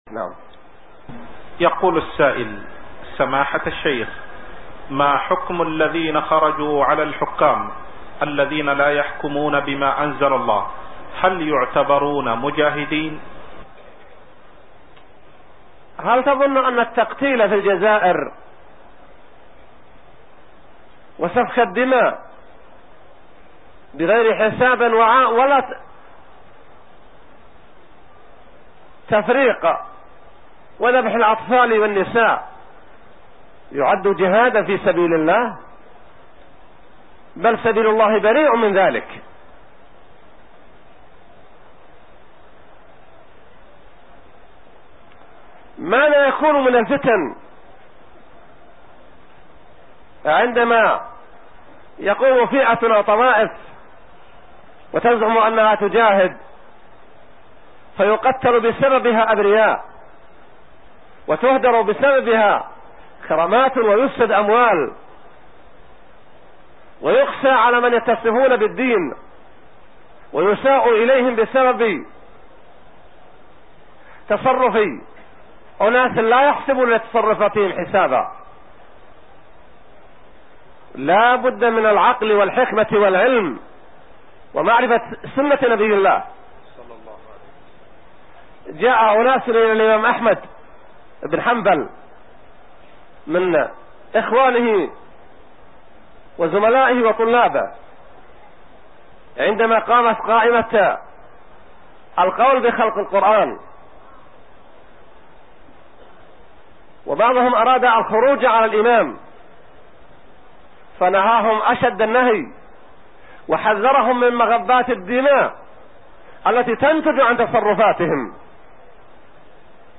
Download audio file Downloaded: 231 Played: 433 Artist: الشيخ صالح اللحيدان Title: نصيحة للذين يخرجون على الحكام الذين لا يحكمون بما أنزل الله Length: 2:33 minutes (329.02 KB) Format: MP3 Mono 16kHz 16Kbps (CBR)